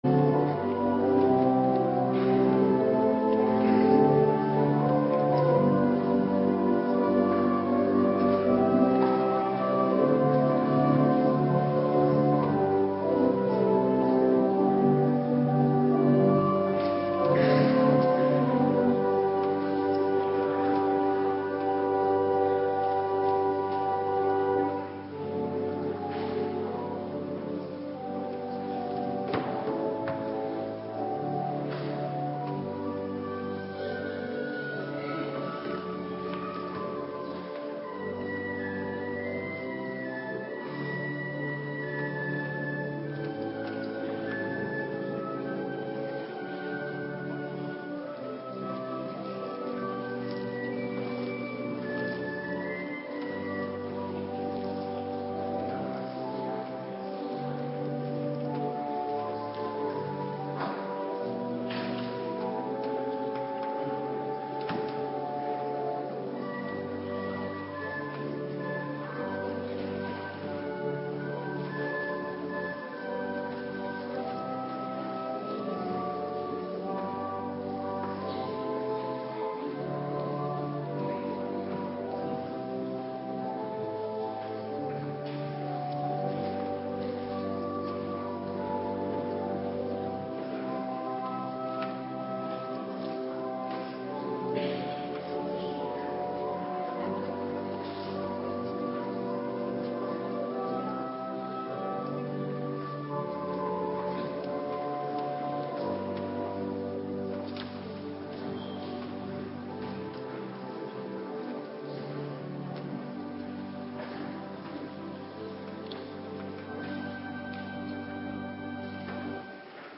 Morgendienst - Cluster 3
Locatie: Hervormde Gemeente Waarder